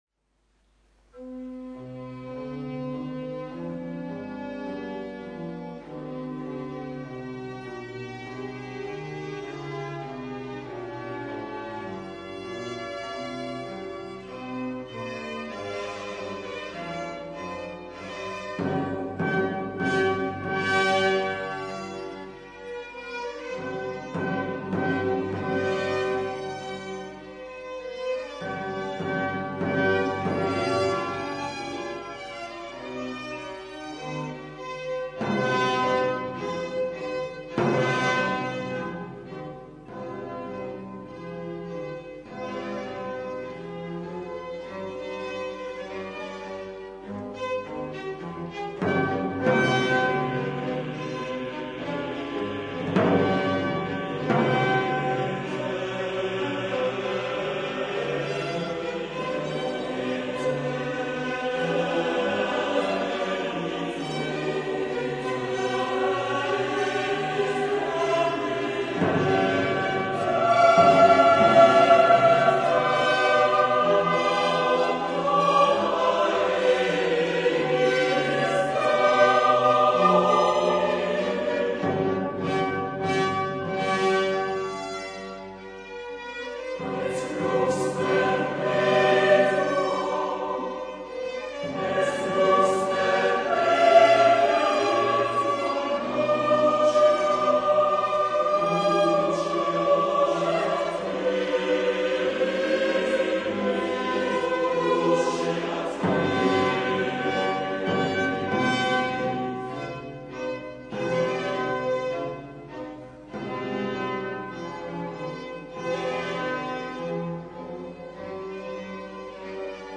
Requiem by Michael Haydn, Concert with the vocal ensemble Arpège at Saint-Seurin Basilica in Bordeaux
MICHAEL-HAYDN-Requiem-début-Concert-Bordeaux-Ensemble-Vocal-Arpège.mp3